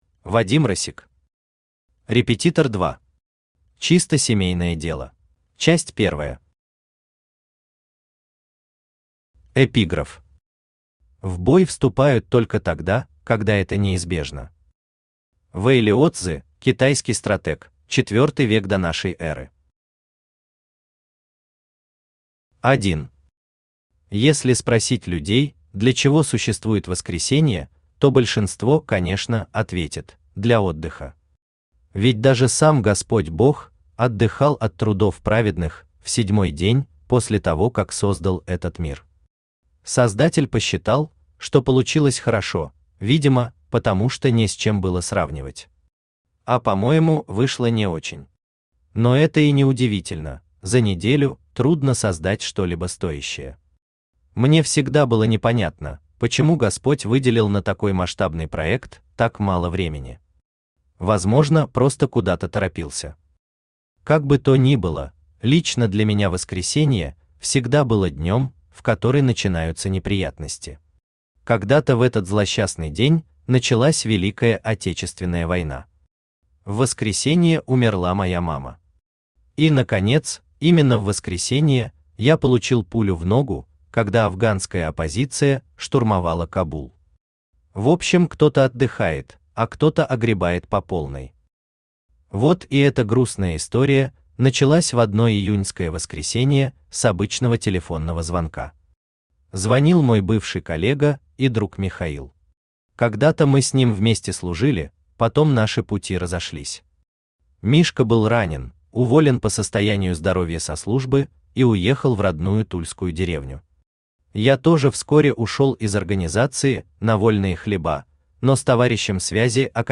Аудиокнига Репетитор 2. Чисто семейное дело | Библиотека аудиокниг
Чисто семейное дело Автор Вадим Россик Читает аудиокнигу Авточтец ЛитРес.